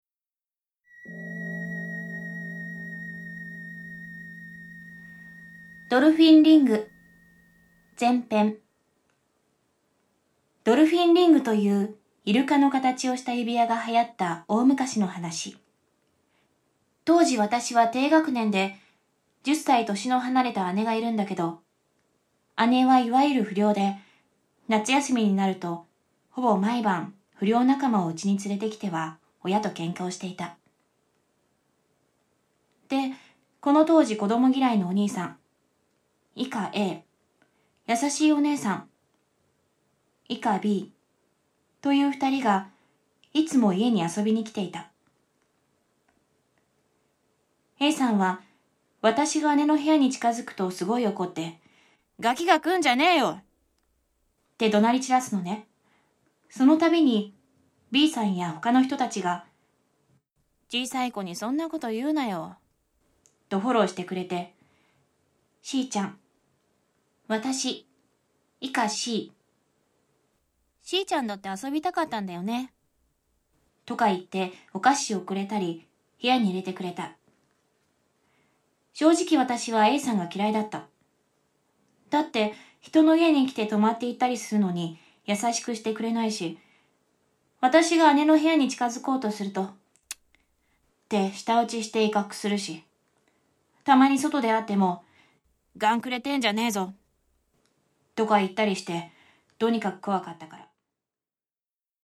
「ドルフィンリング」他、全5話28分の怪談を収録。SEにもこだわり、最先端技術を駆使し、擬似的に3D音響空間を再現、格別の臨場感を体感出来ます！